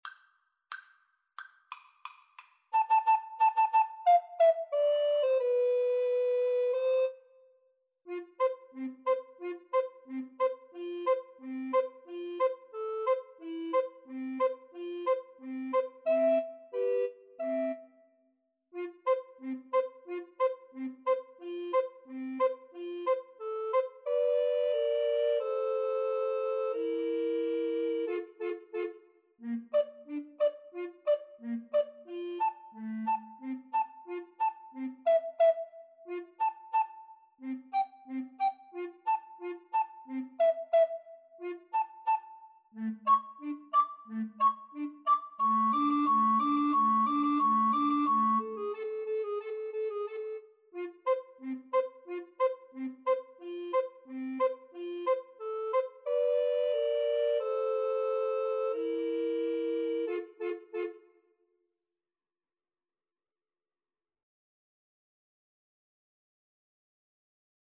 Allegro =180 (View more music marked Allegro)